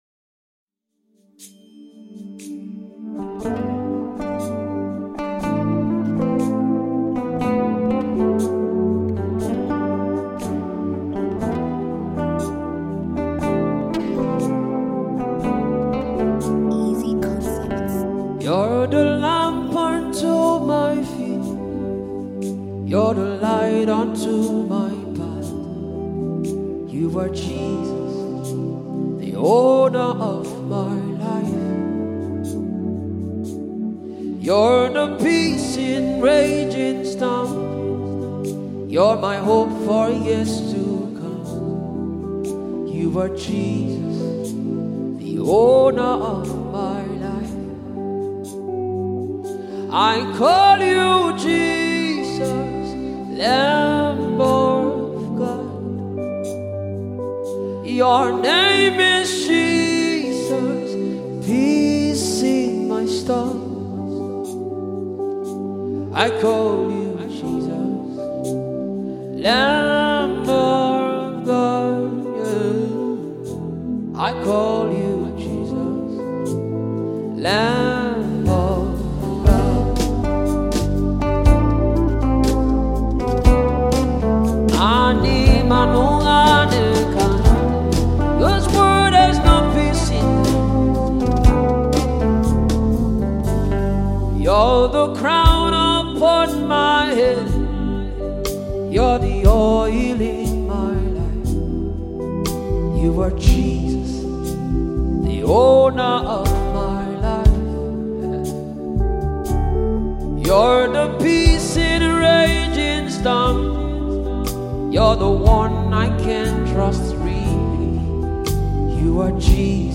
Phenomenon Nigerian gospel singer and songwriter